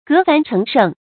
革凡成圣 注音： ㄍㄜˊ ㄈㄢˊ ㄔㄥˊ ㄕㄥˋ 讀音讀法： 意思解釋： 革除凡習，成為圣哲。